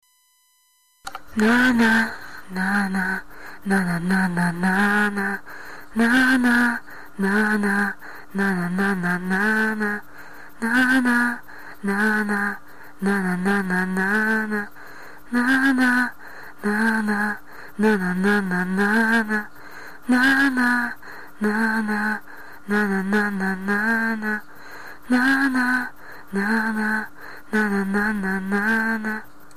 Иностранная песня, помню только мотив припева и несколько неточных слов: I know feel now that i just you self...